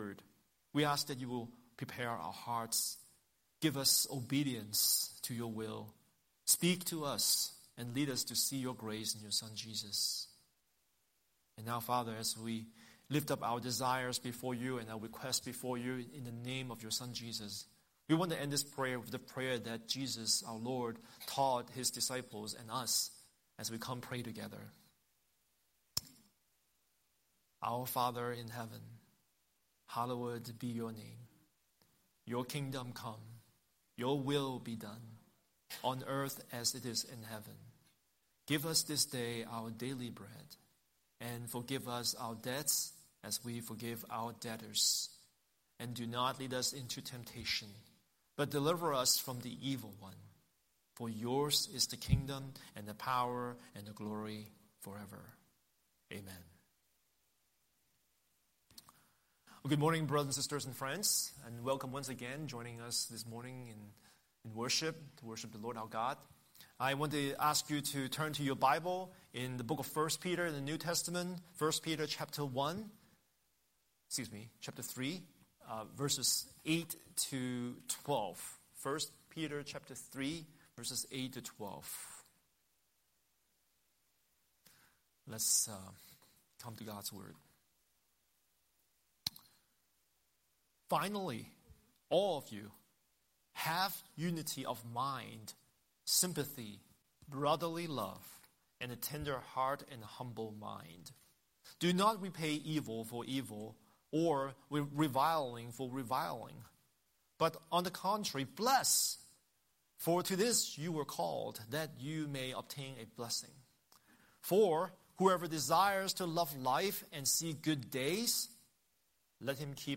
Scripture: Isaiah 9:6–7 Series: Sunday Sermon